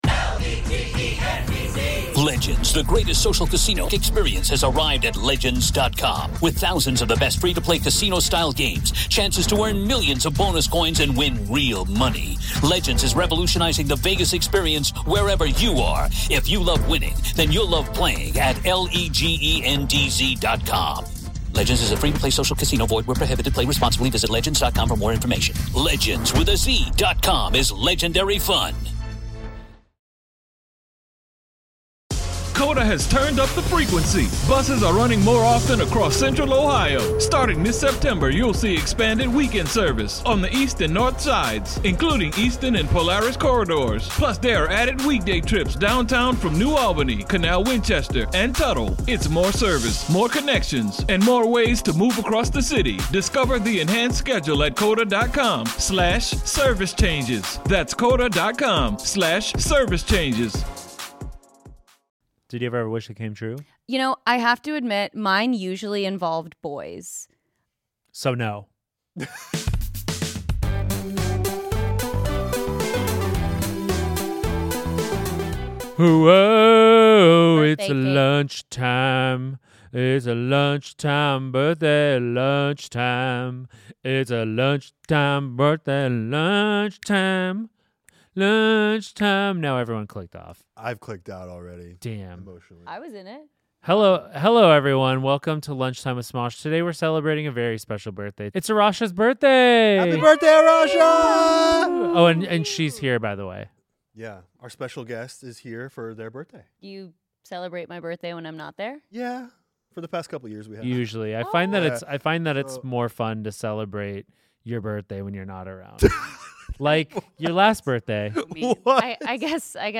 Loud sound warning @ 54:19